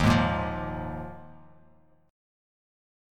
D#sus2sus4 Chord
Listen to D#sus2sus4 strummed